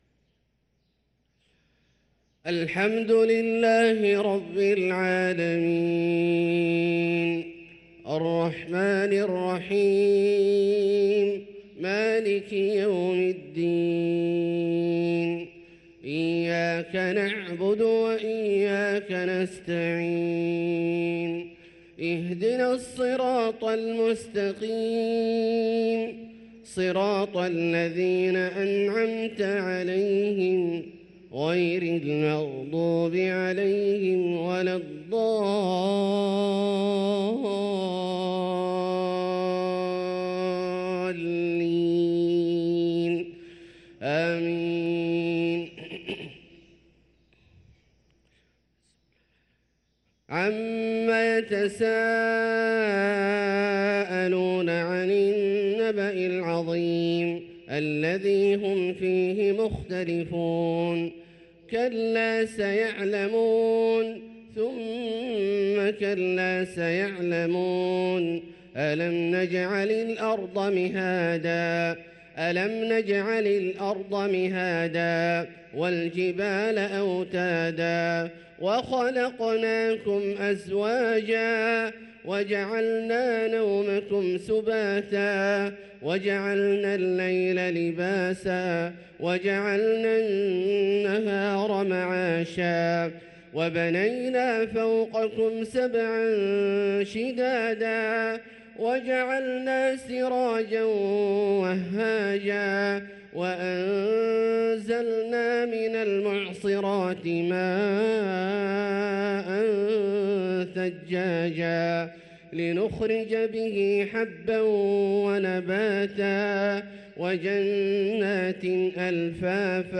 صلاة الفجر للقارئ عبدالله الجهني 16 جمادي الآخر 1445 هـ
تِلَاوَات الْحَرَمَيْن .